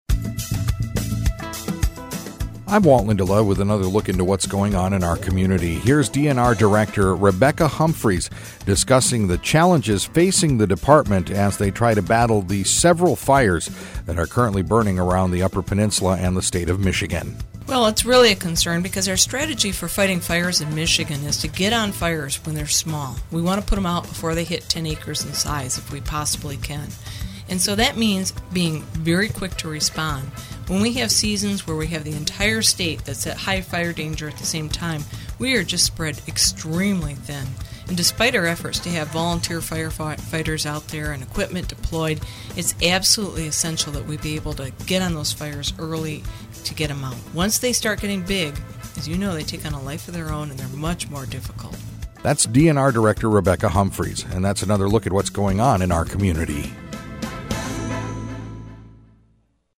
Rebecca Humphries, Director, MI DNR – Fire crews stretched to the limit.